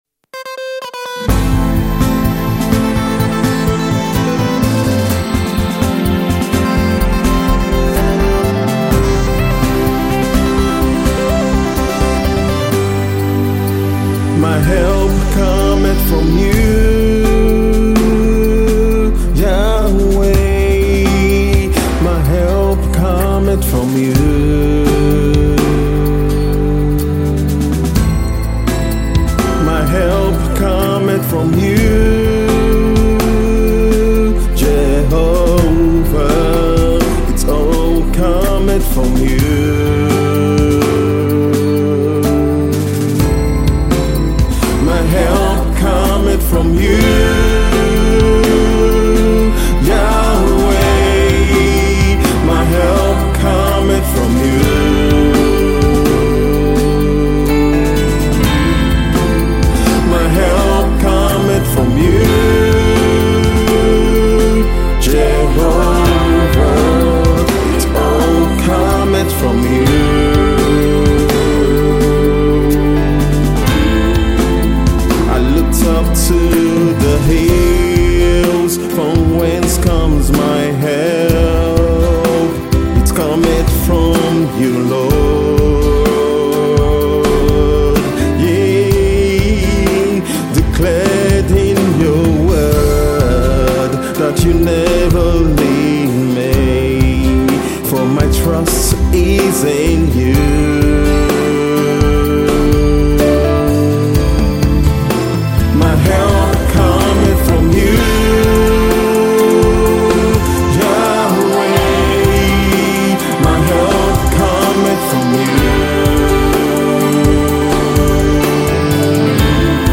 A reviving gospel song for your closer fellowship with GOD.